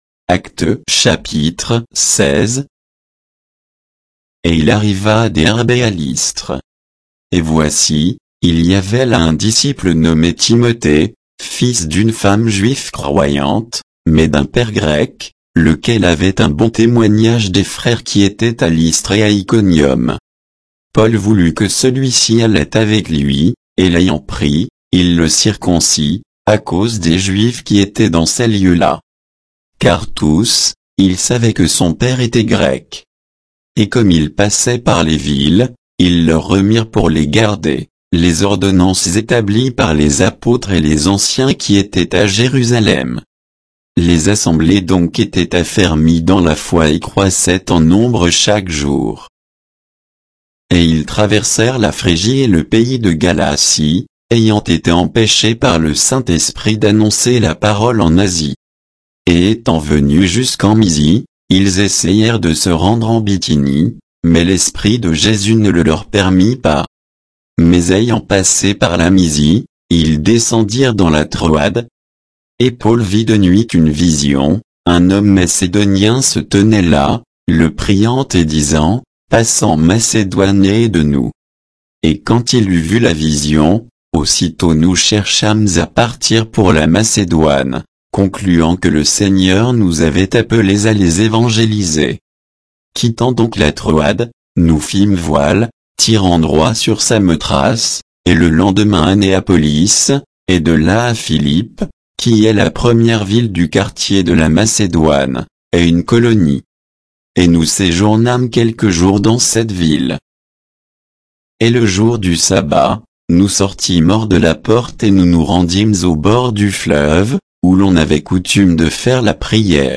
Bible_Actes_16_(texte_uniquement).mp3